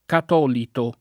catolito [ kat 0 lito ]